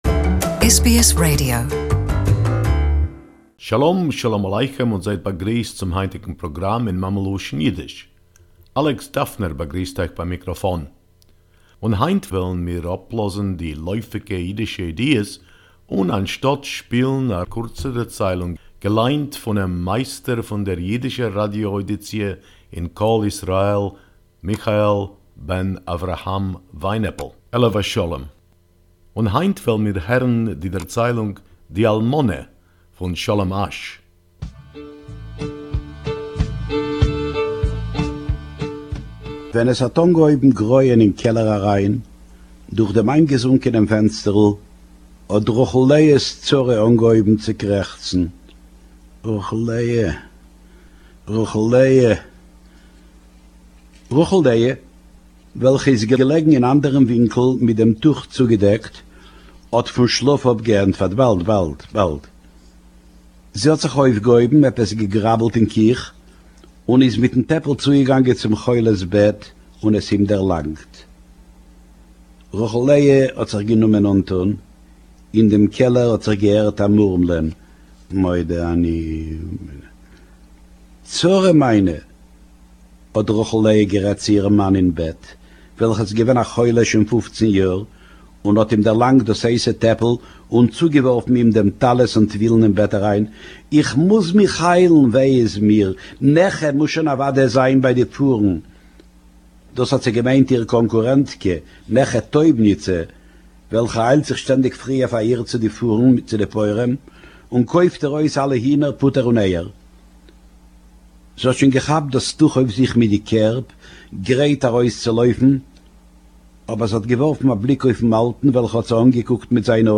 Yiddish Story, Di Almoneh, The Widow by Sholem Asch